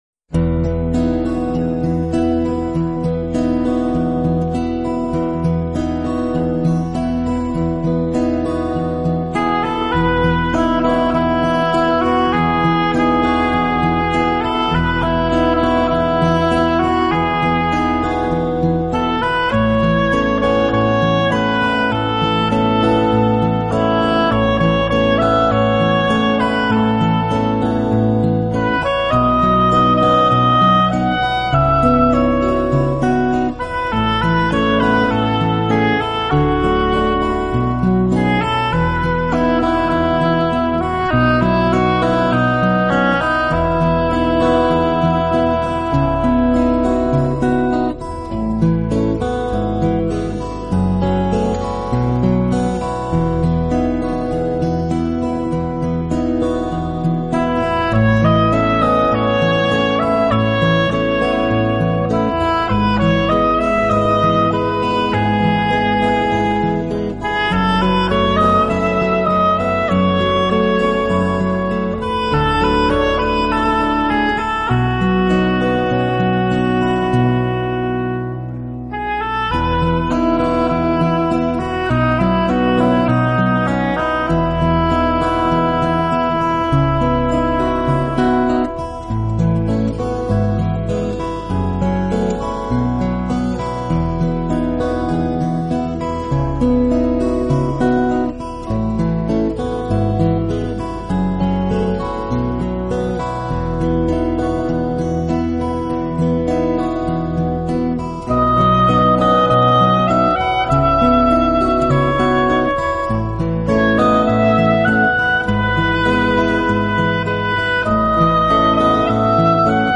音乐类型：New Age
感觉，幽幽竖笛加上丝丝吉它拨弹，更能凸显其录音质素！